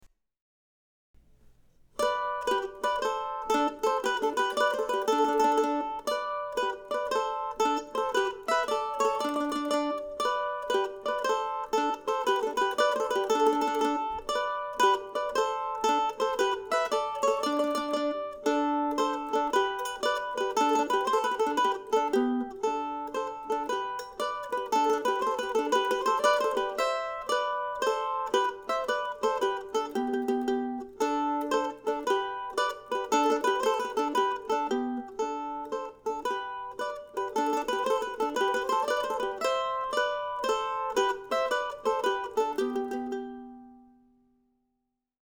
Here also is no. 7 of William Bates' duettinos, adapted for the mandolin.